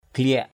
/kliaʔ/ (d.) ghế bành.